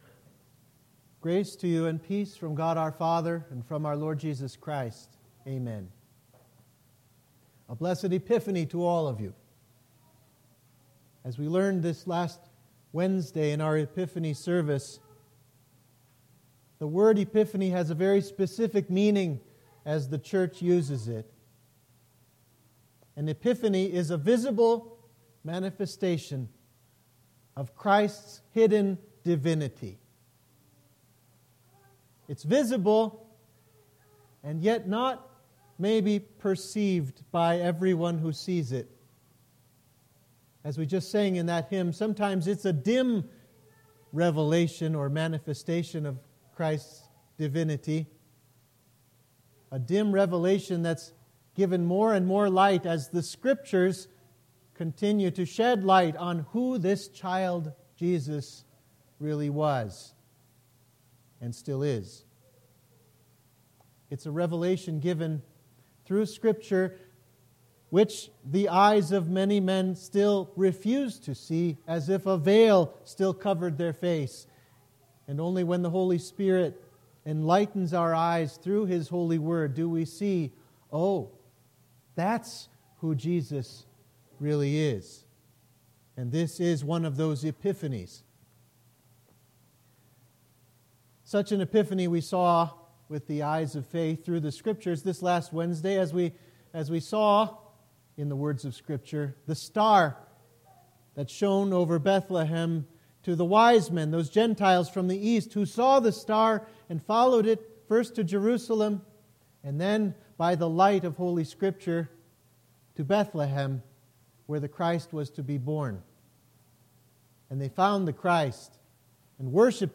Sermon for the First Sunday after Epiphany